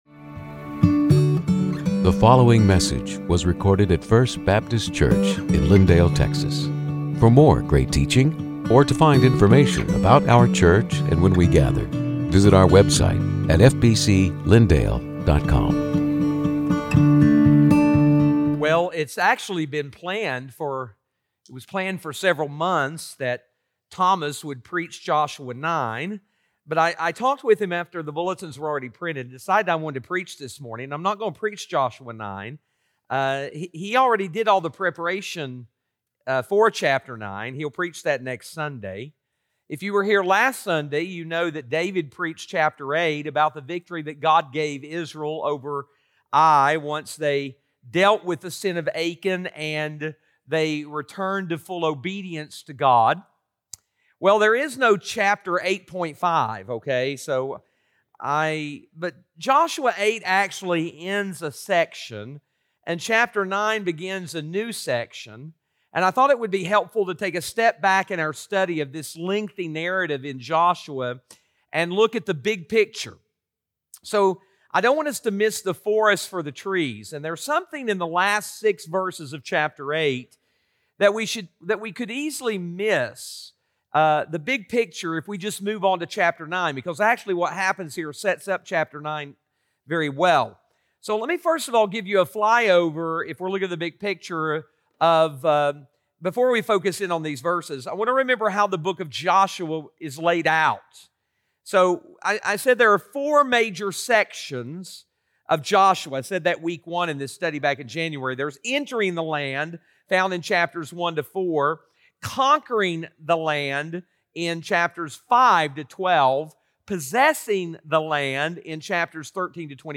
Sermons › Joshua 8:30-35